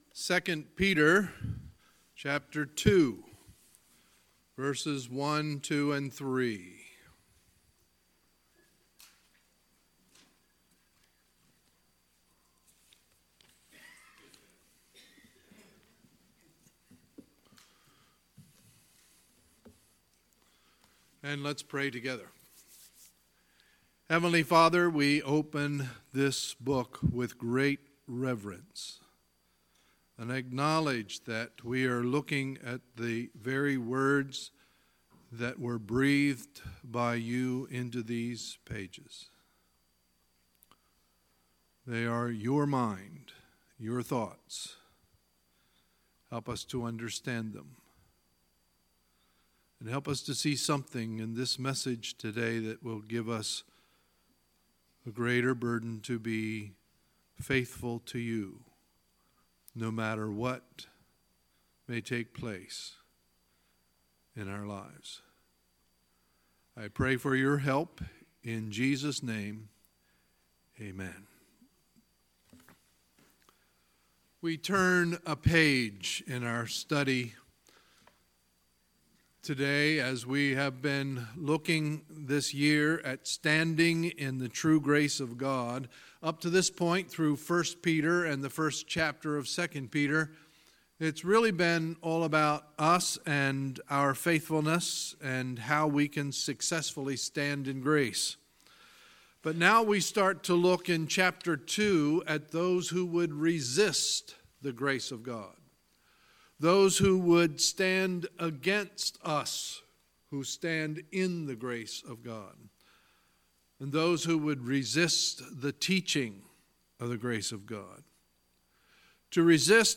Sunday, October 14, 2018 – Sunday Morning Service